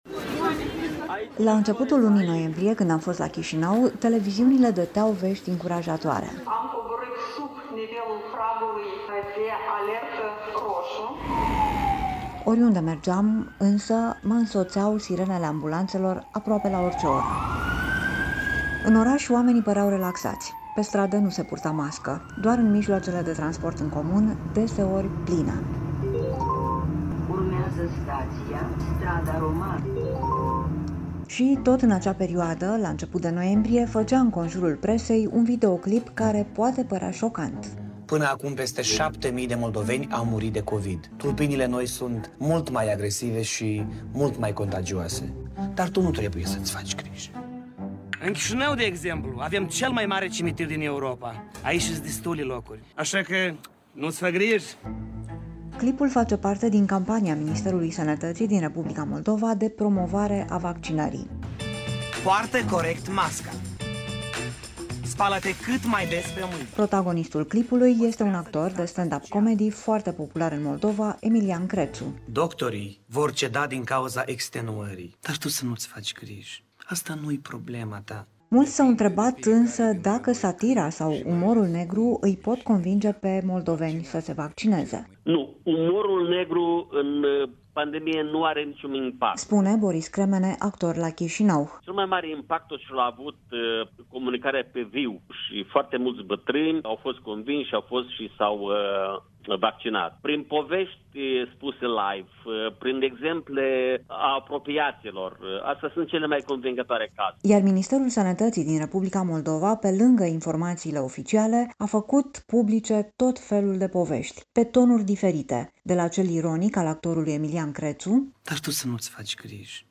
Reportaj: Republica Moldova, fake-news-ul și vaccinarea | Lumea Europa FM